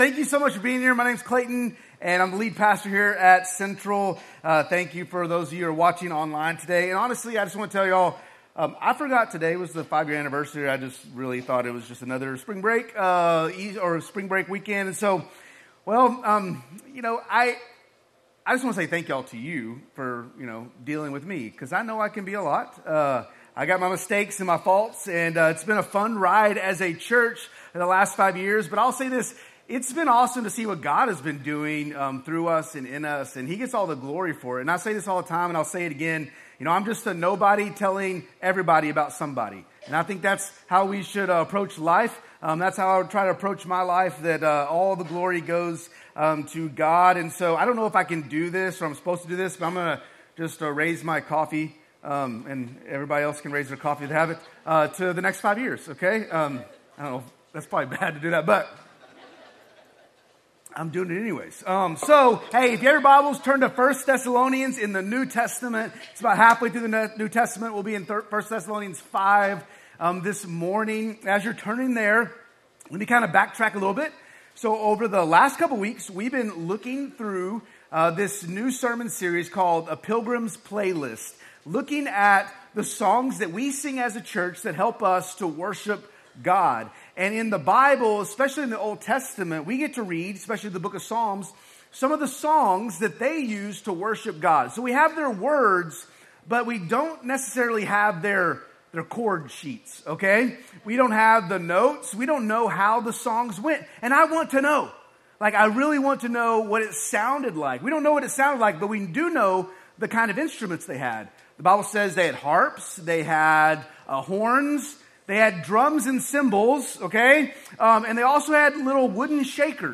Week 3 of our sermon series "Pilgrim's Playlist" - "I Thank God"